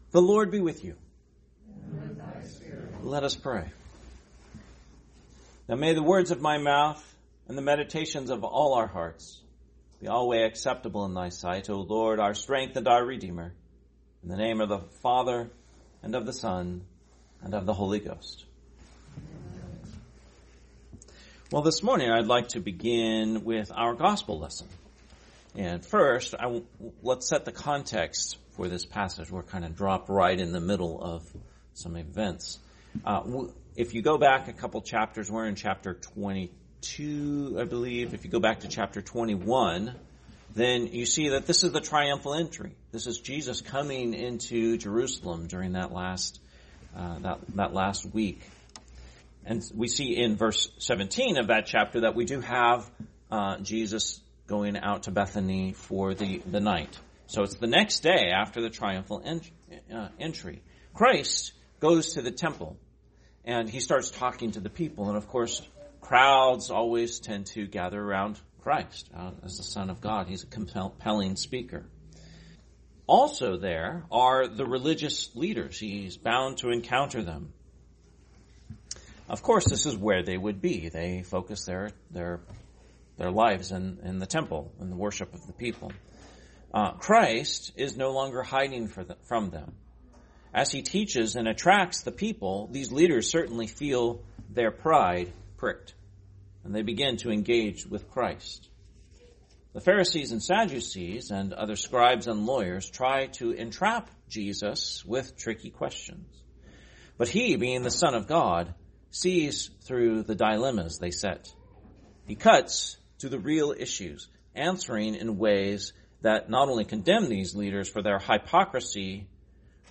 Sermon, 18th Sunday after Trinity, 2025